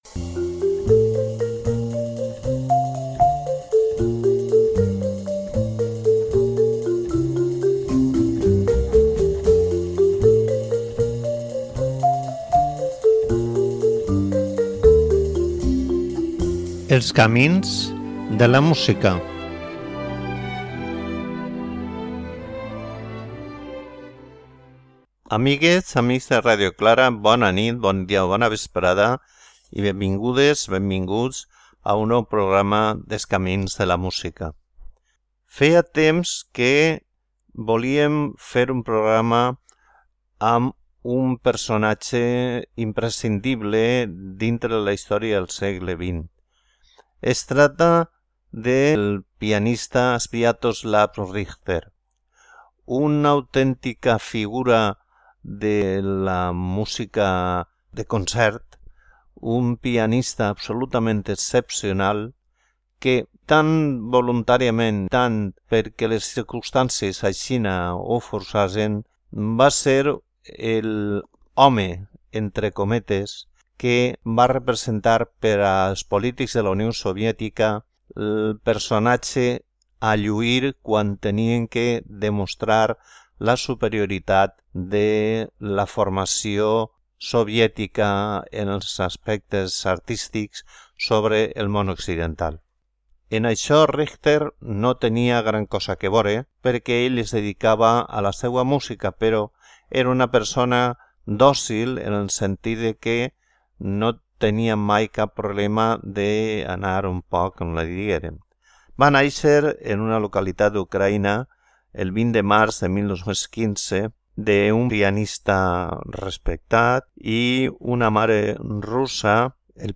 Música pera piano sols, que destaca encara més la seua mestria en l'instrument.